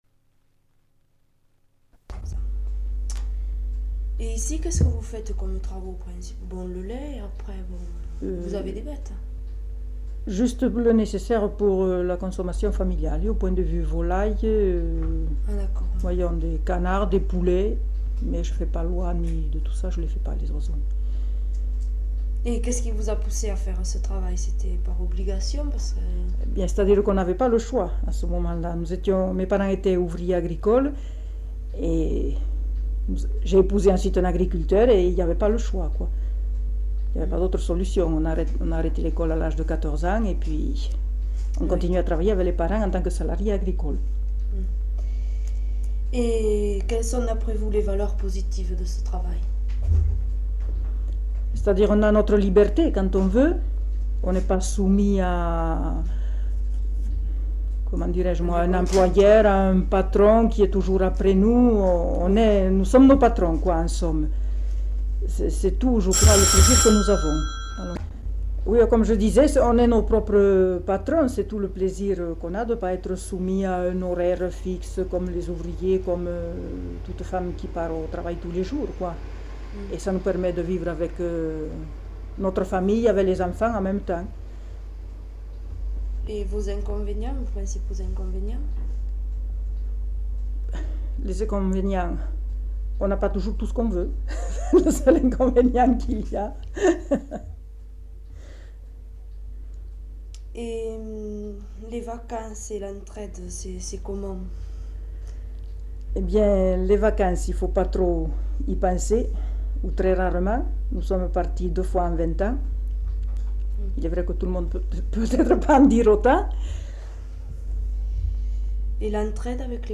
Lieu : Garravet
Genre : récit de vie